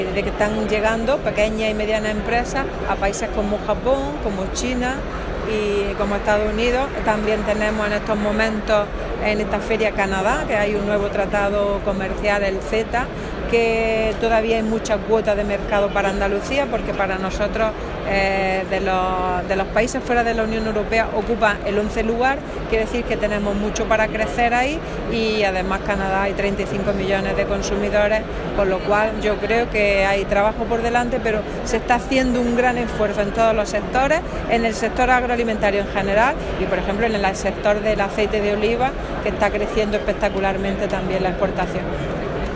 Declaraciones de Carmen Ortiz sobre exportaciones agroalimentarias andaluzas a países extracomunitarios